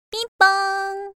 Windowsの起動音や警告音を中心に、パソコンの効果音として使える音声のセットです(全25個)。